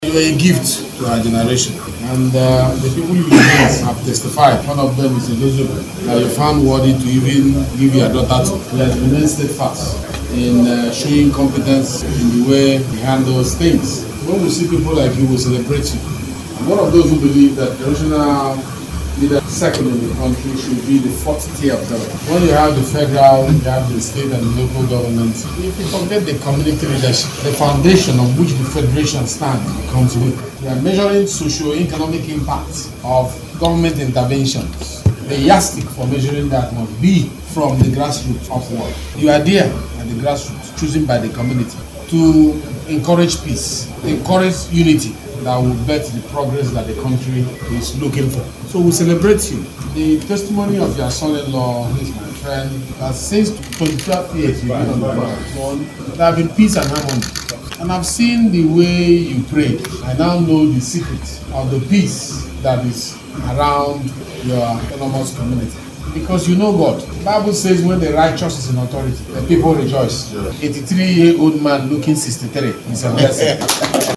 This was made known by the Deputy Speaker House of Representatives Rt, Hon. Benjamin Kalu at the 25th anniversary of His Royal Majesty Eze Sir Prof. Ezeudo. S. Ifenwata’s coronation as the Traditional Ruler of Ugwu Ibere Autonomous community Ikwuano LGA of Abia State as well as the 83rd birthday celebration of the revered Monarch.